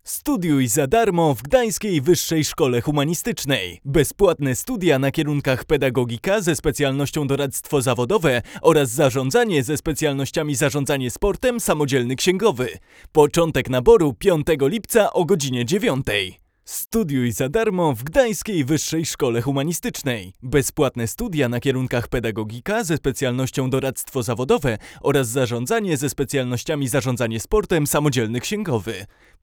Jest czysto, gładko i wyraźnie, a jednocześnie naturalnie.